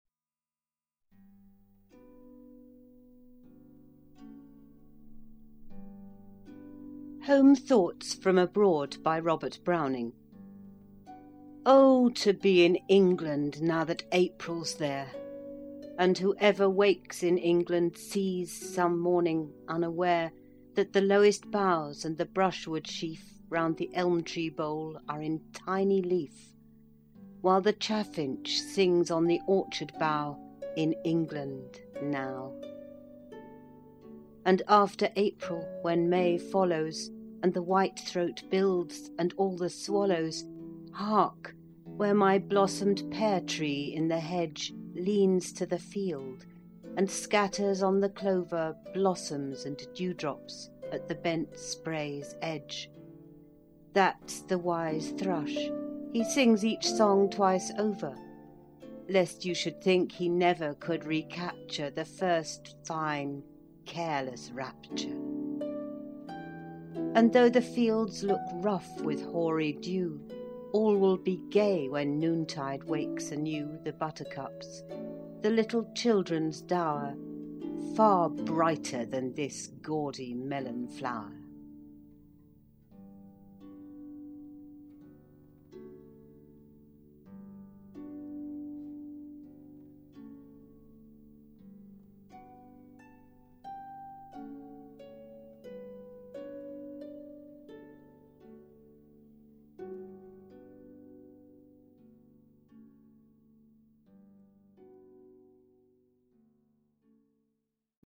Music: Gymnopedie 1 (Satie).